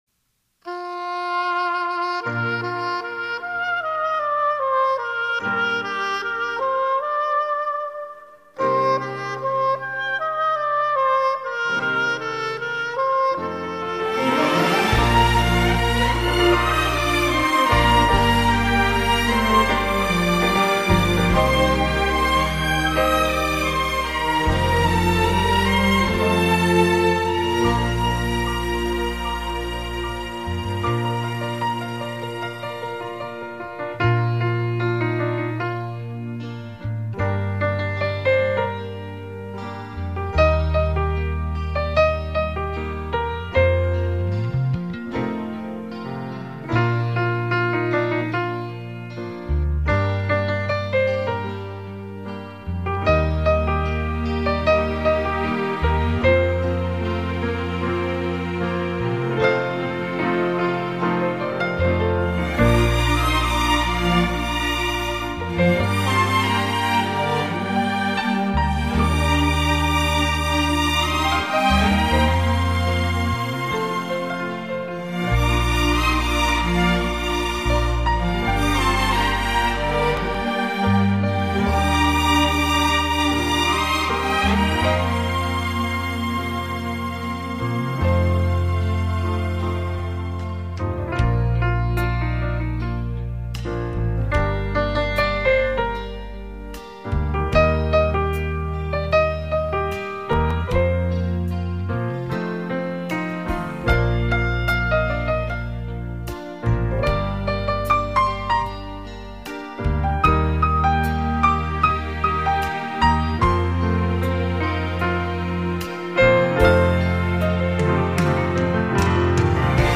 百万钢琴与大交响乐团的超重级制作，气势恢宏、王牌演绎。
价值百万美金的斯坦威钢琴与管弦乐队大器合奏，交相辉映，织成五彩丹霞，充满激情与浪漫。
专辑由国家级交响乐团演出，台北专家录音制作，是海峡两岸文化交流十分成功的代表作品。